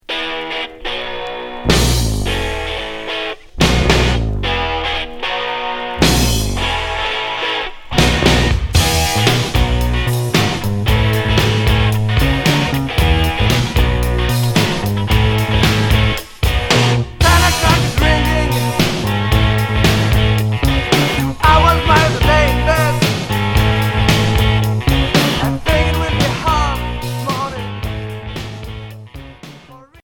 Rock punk Unique 45t